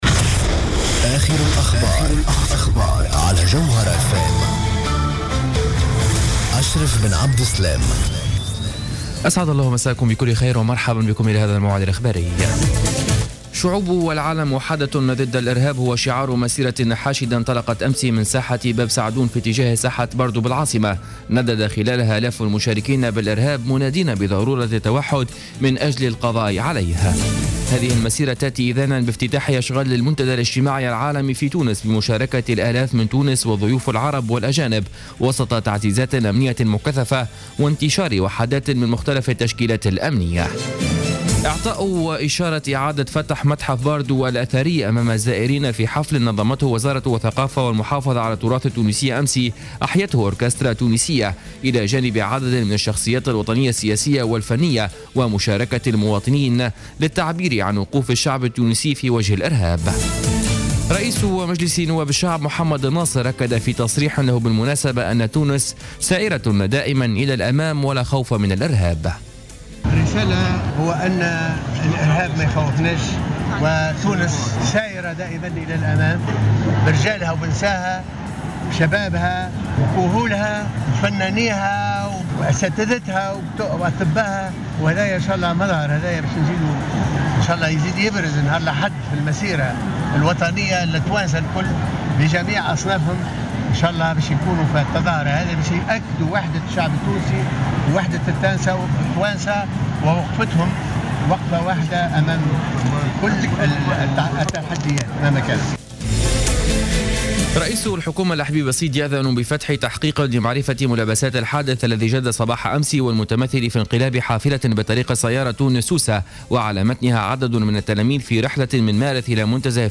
نشرة أخبار منتصف الليل ليوم الاربعاء 25 مارس 2015